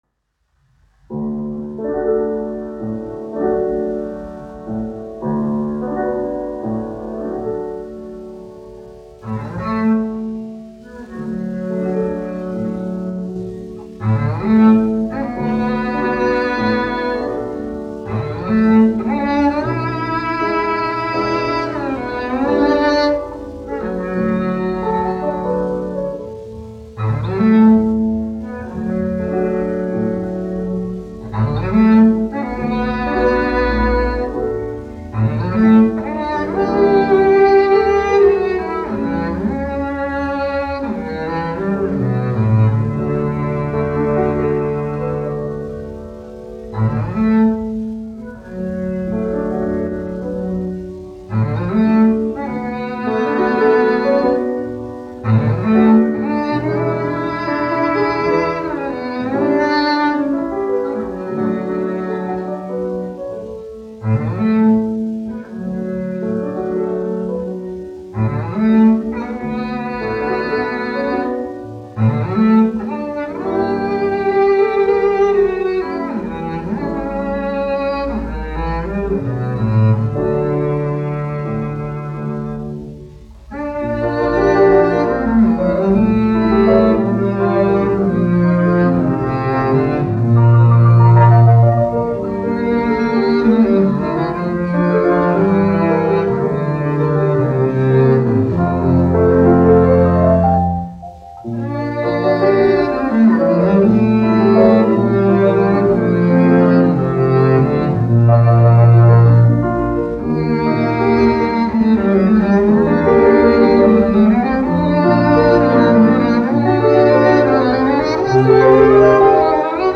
1 skpl. : analogs, 78 apgr/min, mono ; 25 cm
Kontrabasa un klavieru mūzika, aranžējumi
Latvijas vēsturiskie šellaka skaņuplašu ieraksti (Kolekcija)